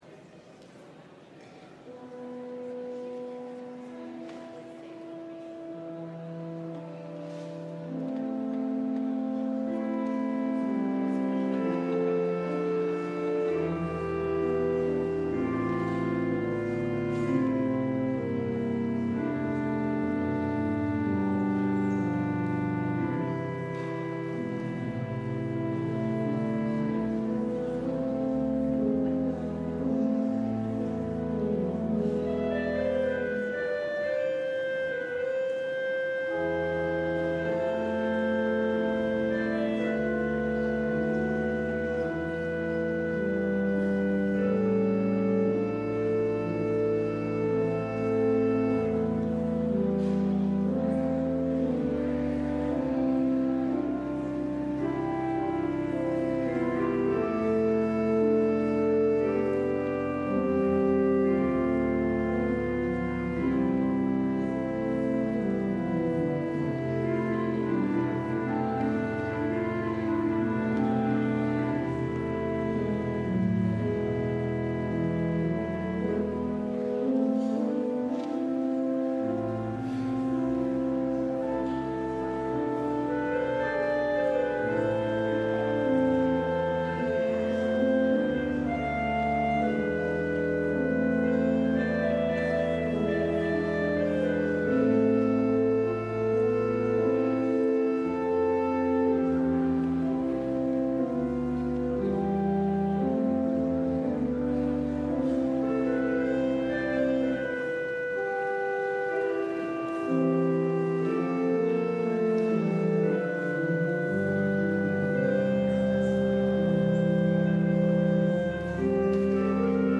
LIVE Morning Worship Service - Faith 101: Atonement